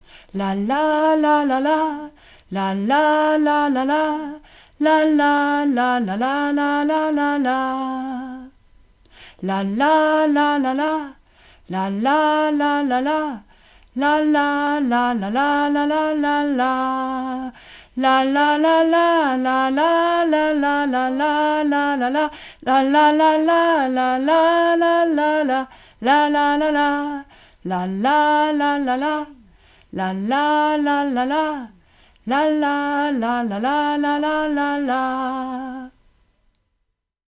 Runterladen (Mit rechter Maustaste anklicken, Menübefehl auswählen)   Bei mir bistu shein (Alt - Refrain 1 - La-La)
Bei_mir_bistu_shein__1a_Alt_Refrain1_LaLa.mp3